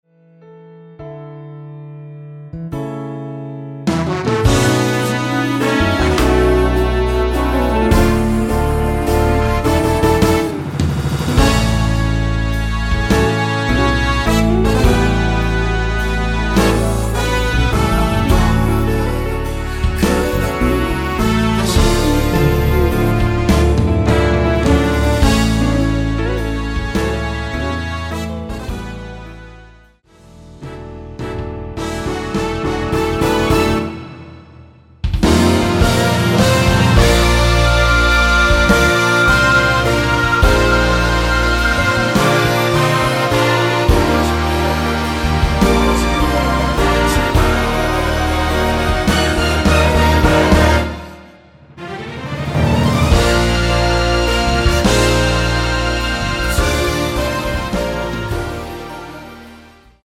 (-1) 내린 코러스 포함된 MR 입니다.(미리듣기 참조)
◈ 곡명 옆 (-1)은 반음 내림, (+1)은 반음 올림 입니다.
앞부분30초, 뒷부분30초씩 편집해서 올려 드리고 있습니다.
중간에 음이 끈어지고 다시 나오는 이유는